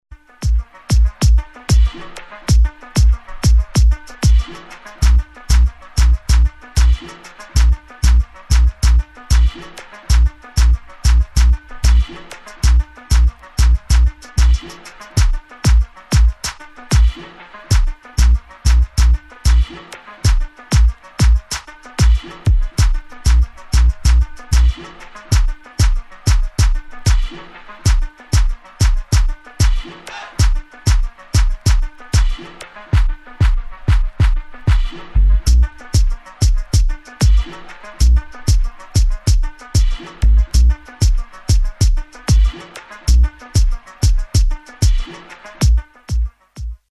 [ BASS / DUB / REGGAE ]
Instrumental